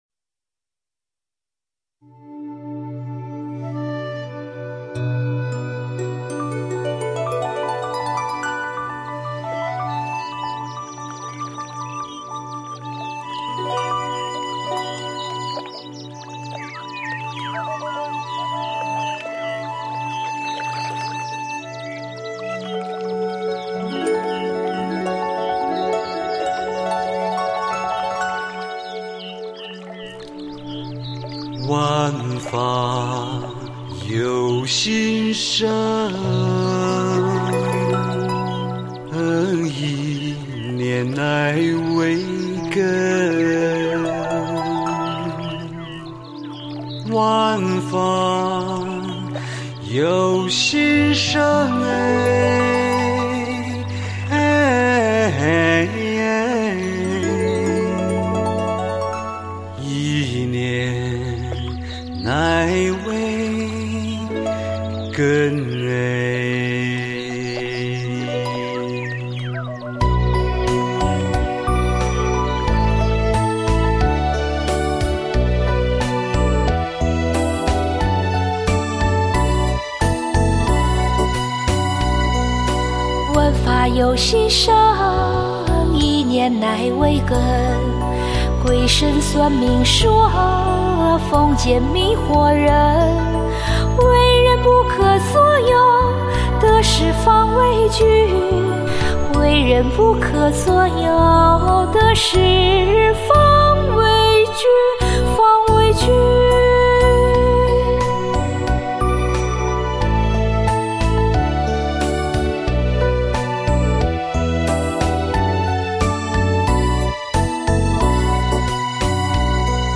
【这是一首四川民间音乐风格的歌曲，首句的自由板清晰自然，在故乡熟悉的旋律中哲言不断重复中，深入思绪，沁入人心】